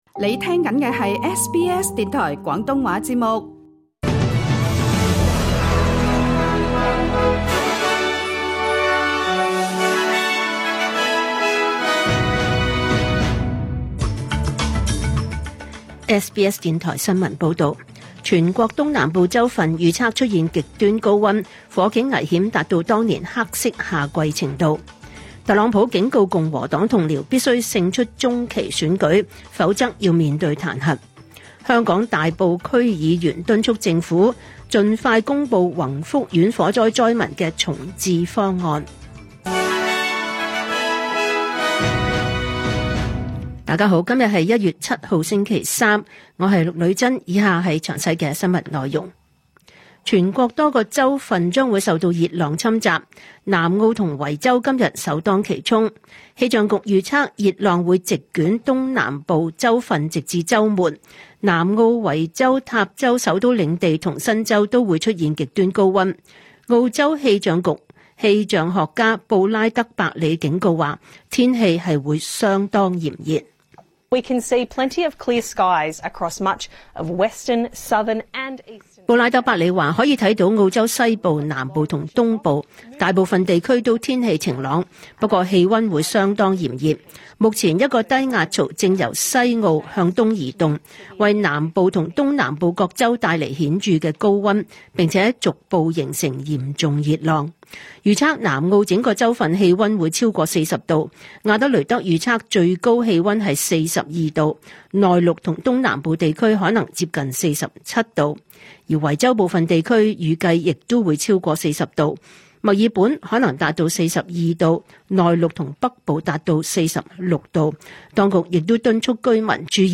2026 年 1 月 7 日 SBS 廣東話節目詳盡早晨新聞報道。